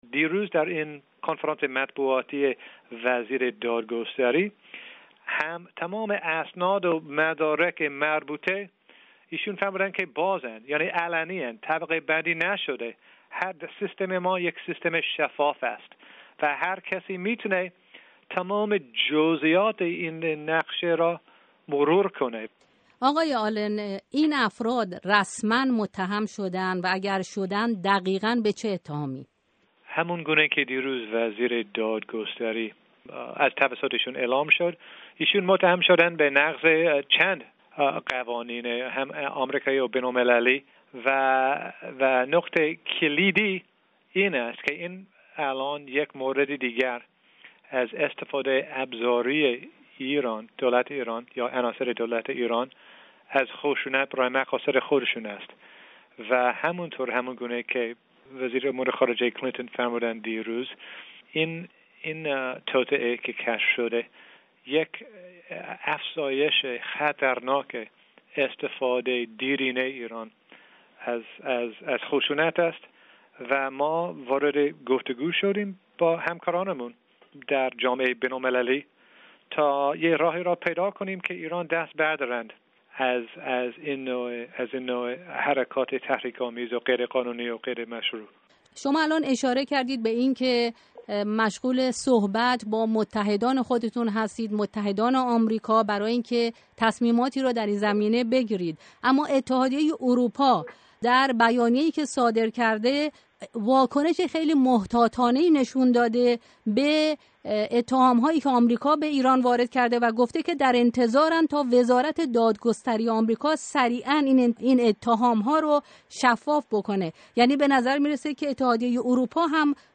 گفت وگوی